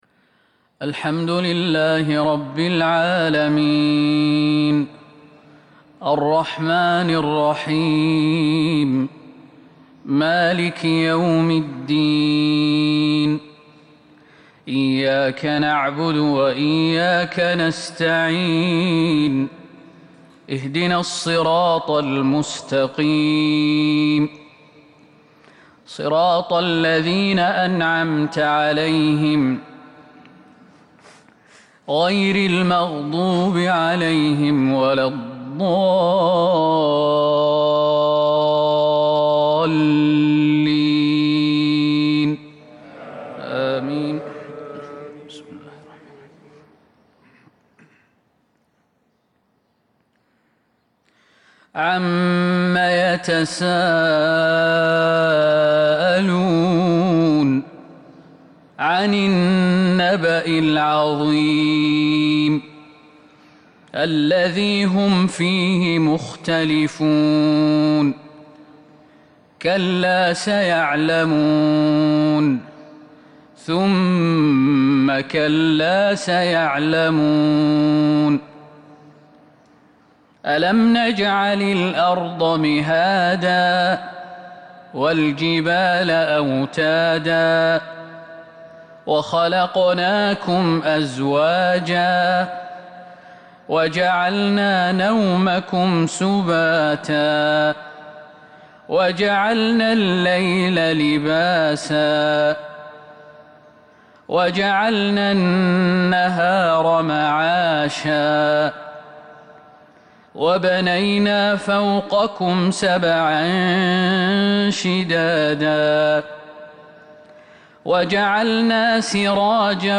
صلاة الفجر 4/1/1442 سورة النبأ salat alfajr 23/8/2020 surat alNabaa > 1442 🕌 > الفروض - تلاوات الحرمين